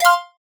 notification_sounds
ascend.ogg